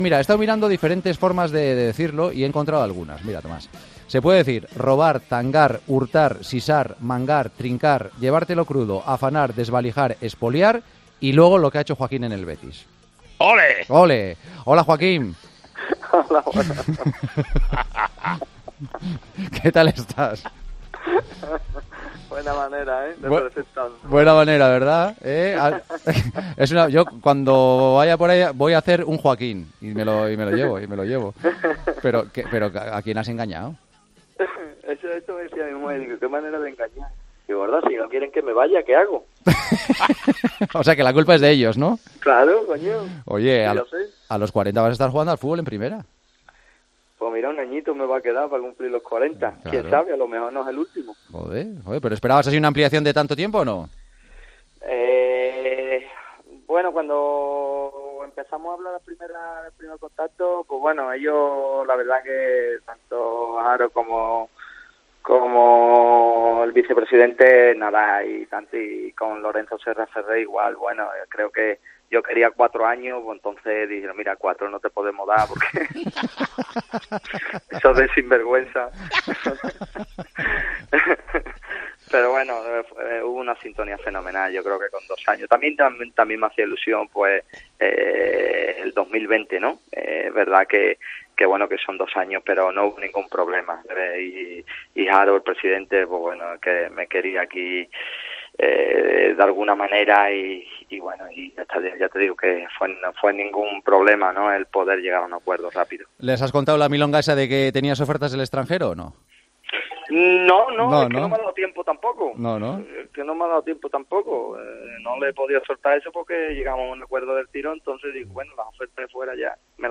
Entrevista en El Partidazo de COPE